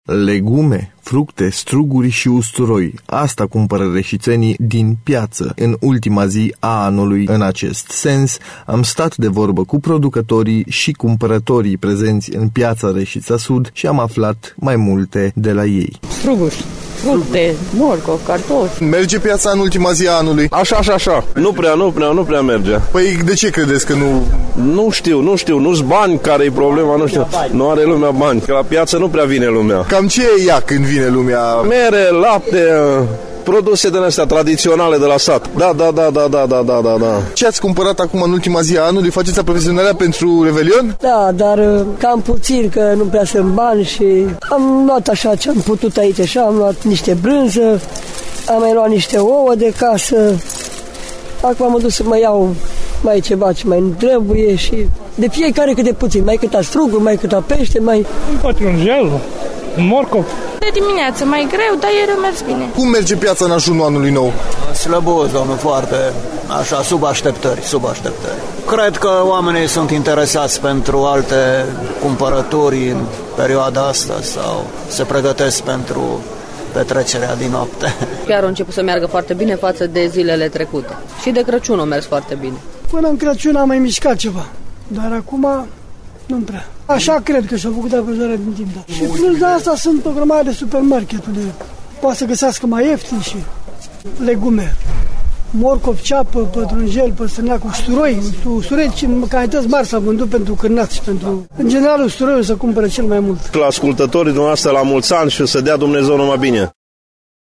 Producătorii sunt nemulţumiţi de concurenţa care vine din partea marilor magazine. Cu toate acestea, în piaţă se simte o atmosferă de sărbătoare.